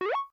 sound_scatter.ogg